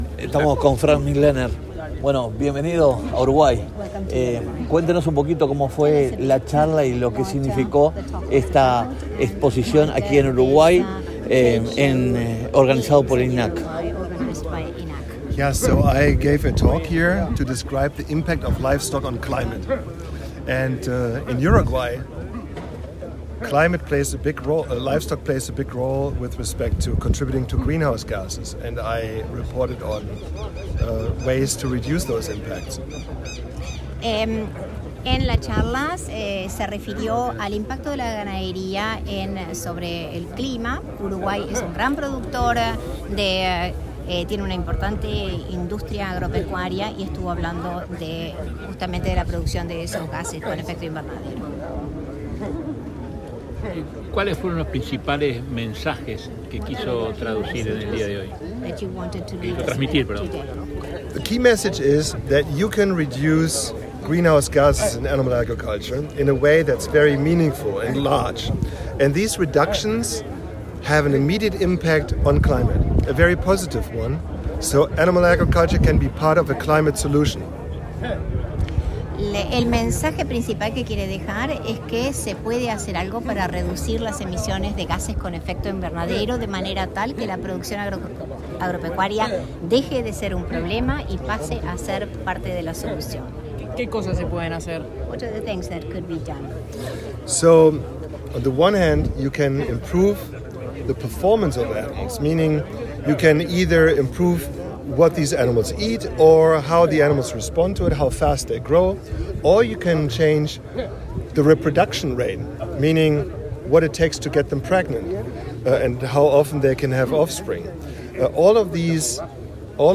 Luego de culminada la conferencia, en entrevista con los periodistas en Expo Melilla, el especialista indicó que el principal mensaje para los productores es que la ganadería puede dejar de ser un problema y se convierta en parte de la solución pues lo más importante es la eficiencia animal, mejorando la alimentación y las etapas productivas para que se reduzca la huella.
audio-nota-periodistas-fm.mp3